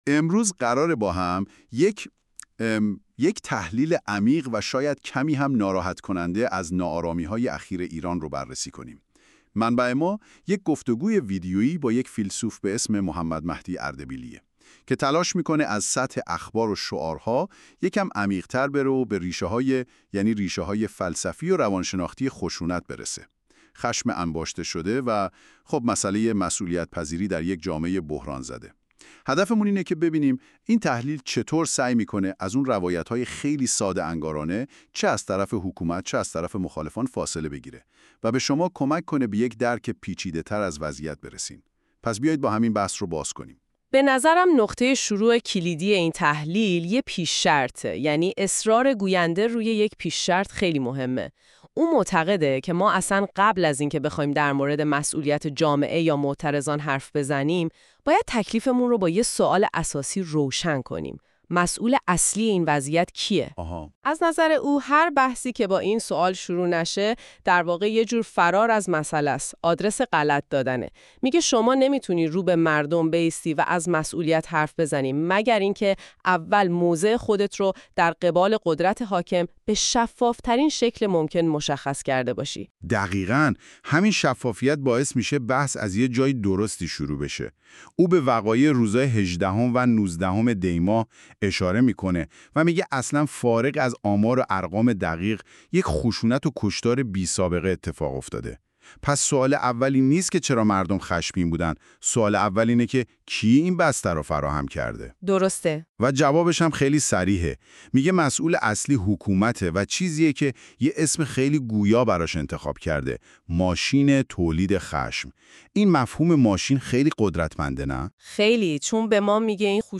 مبارزه در میان هیولاها | گفتگویی